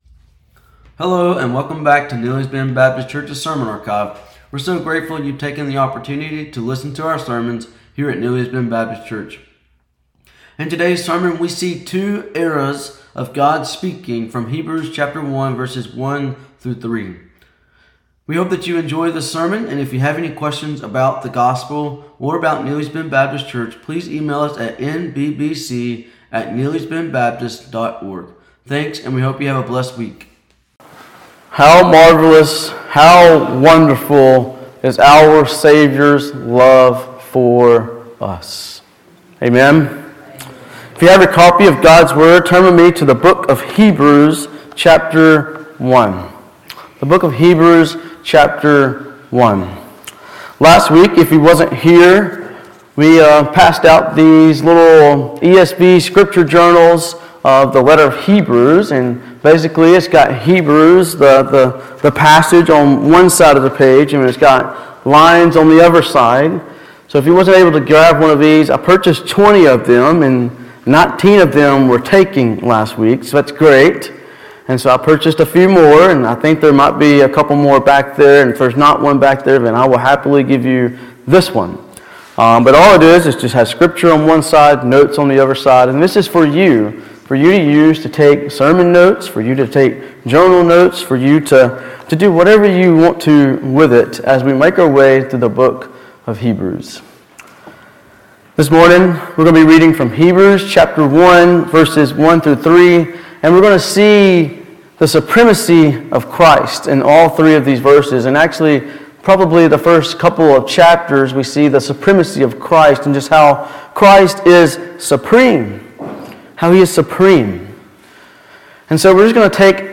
Has God spoken to us? This sermon answers this question for us and helps us to see that God has spoken long ago by the prophets but in these last days, God has spoken by his Son Jesus.